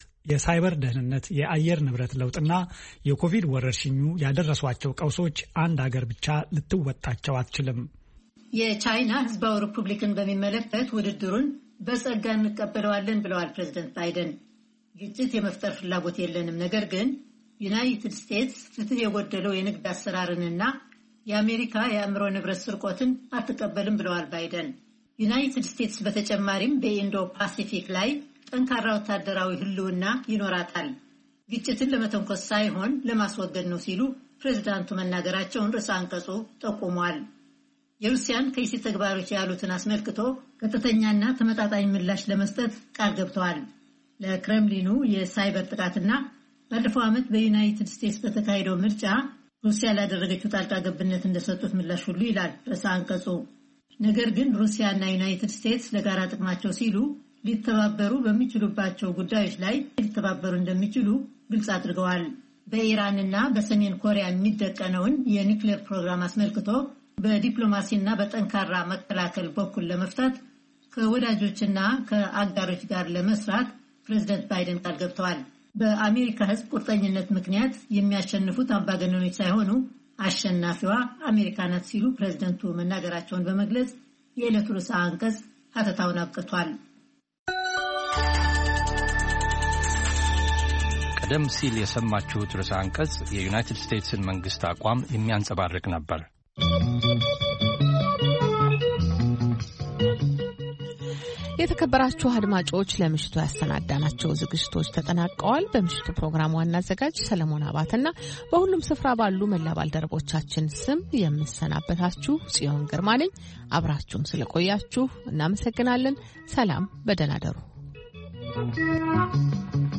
ፈነወ ትግርኛ ብናይ`ዚ መዓልቲ ዓበይቲ ዜና ይጅምር ። ካብ ኤርትራን ኢትዮጵያን ዝረኽቦም ቃለ-መጠይቓትን ሰሙናዊ መደባትን ድማ የስዕብ ። ሰሙናዊ መደባት ዓርቢ፡ ቂሔ-ጽልሚ / ፍሉይ መደብ/ ሕቶን መልስን